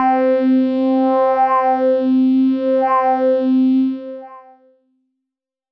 Organesq Pad C4.wav